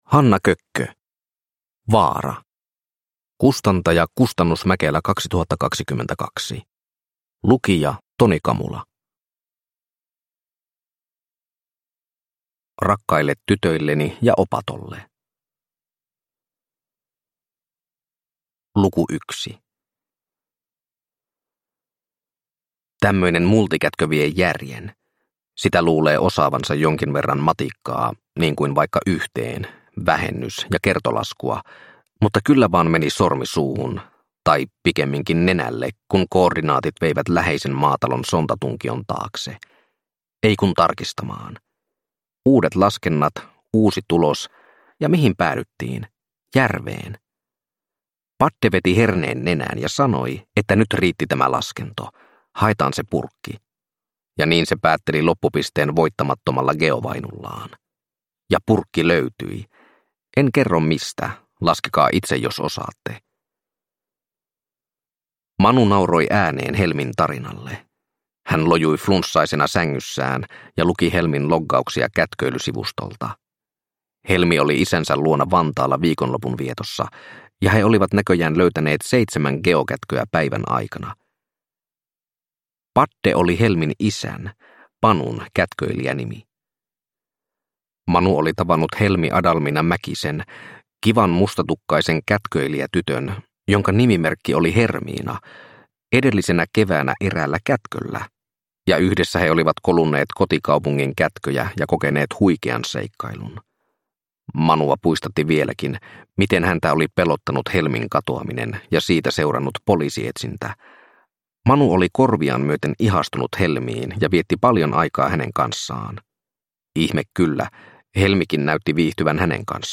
Vaara – Ljudbok – Laddas ner